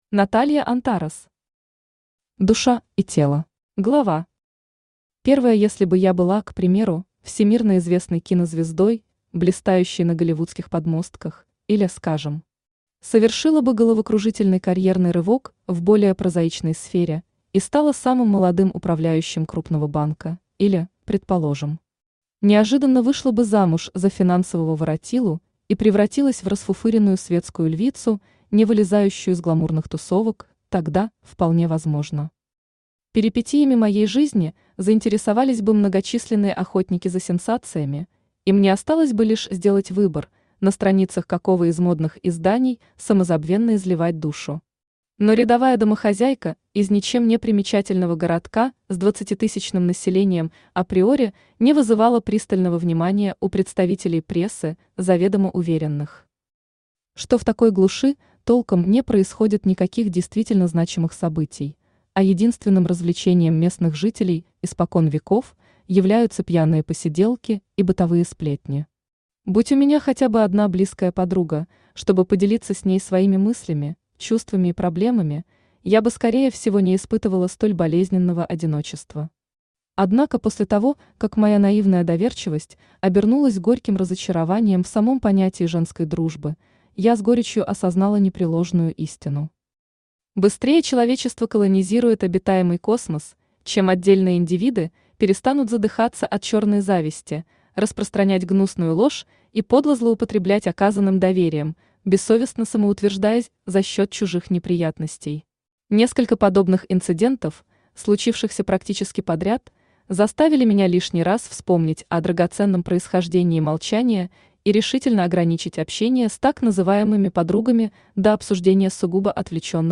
Аудиокнига Душа и тело | Библиотека аудиокниг
Aудиокнига Душа и тело Автор Наталья Антарес Читает аудиокнигу Авточтец ЛитРес.